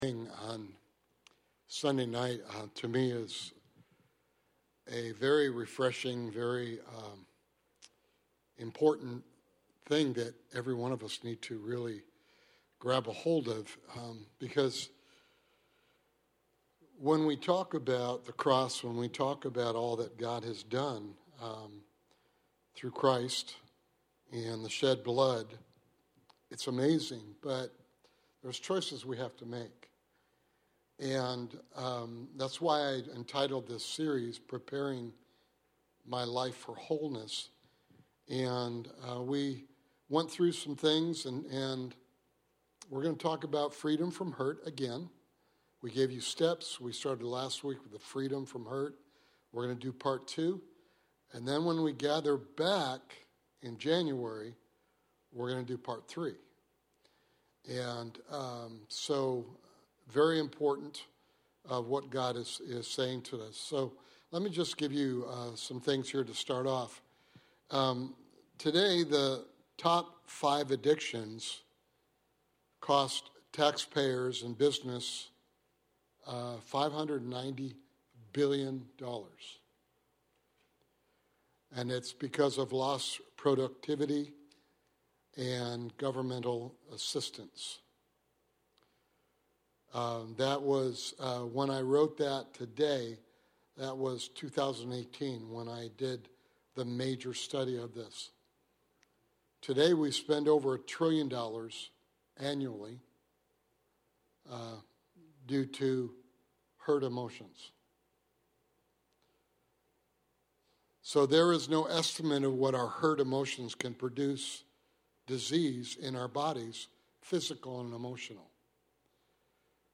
Sunday evening Bible study